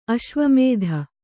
♪ aśvamēdha